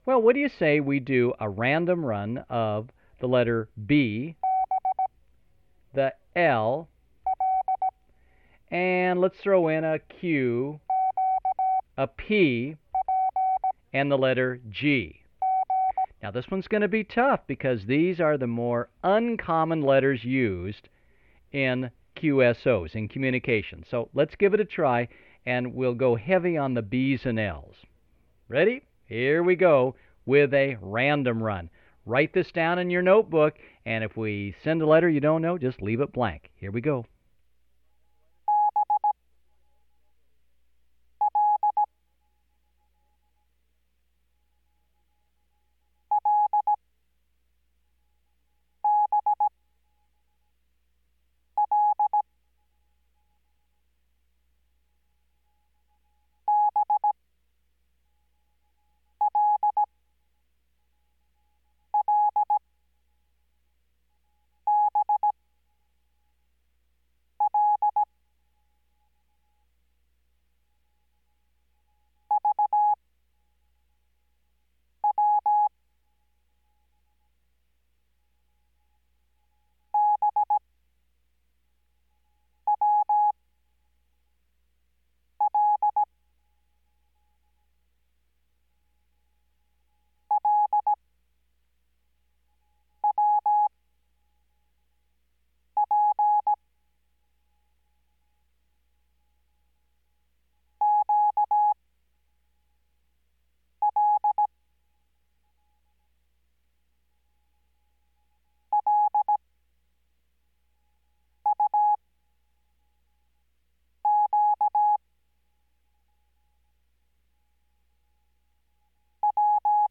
Below this line is 5 wpm
Farnsworth Spacing: 15WPM Character speed Spaced at 5WPM.
Morse Code Course